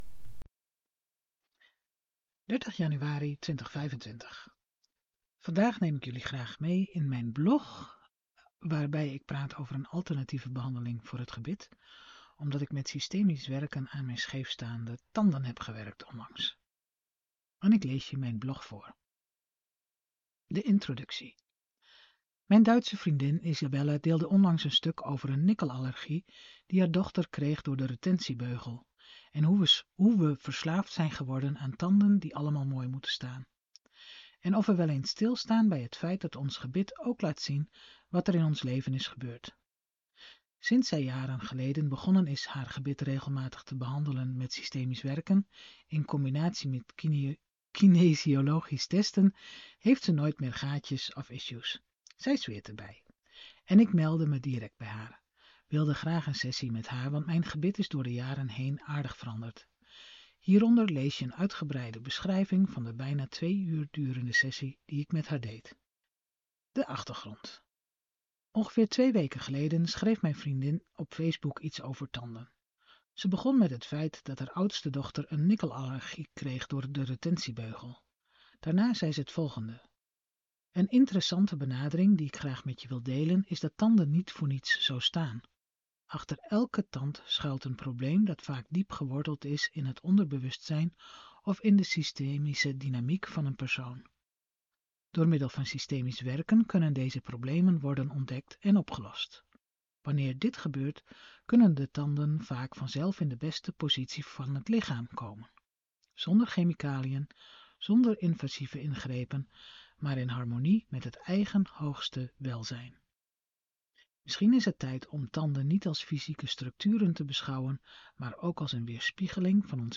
Ik heb de blog ook ingesproken: